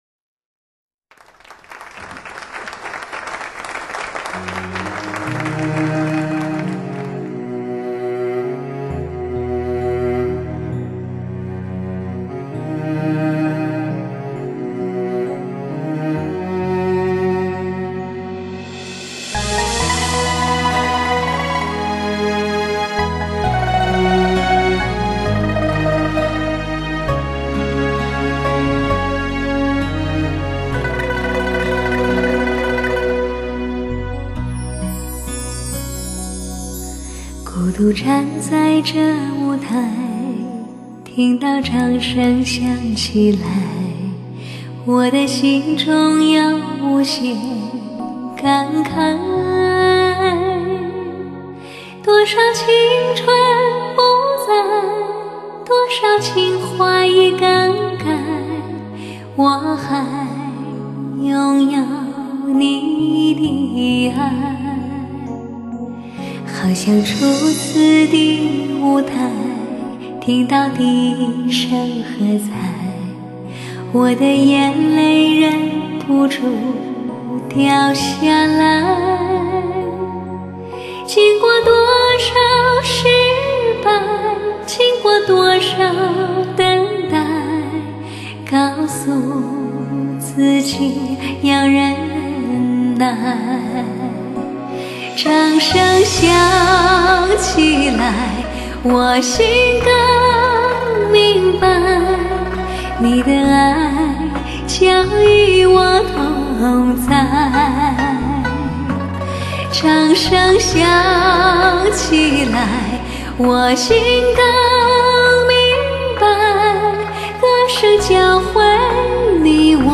炙人口倾情演绎，娓娓动人的甜美歌声，让人魂牵梦绕。